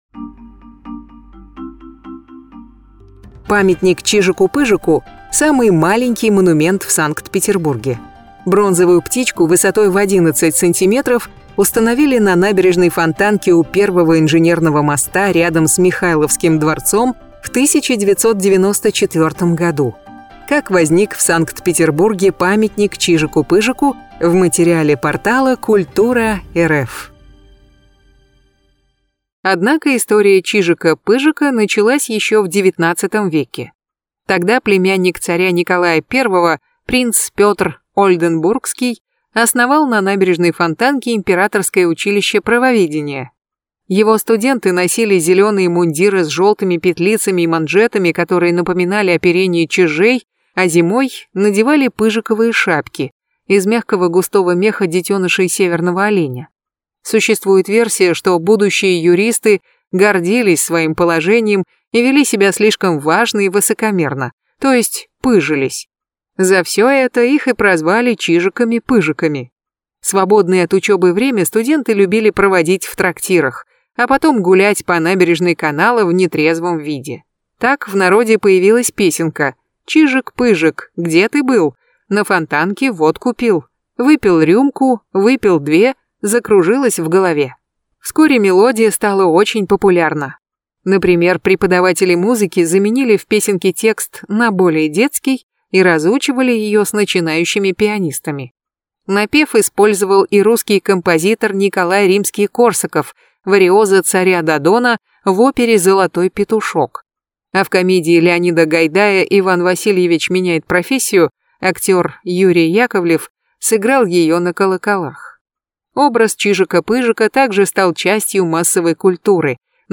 🔈 Лекторий выходного дня: